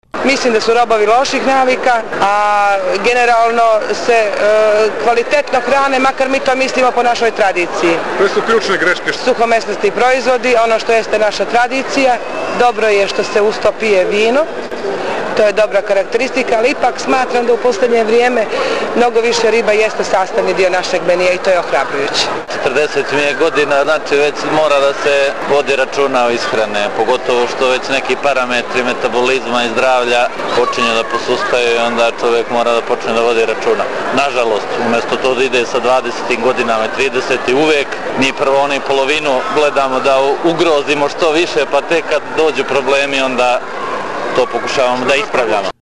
Anketa građana Podgorice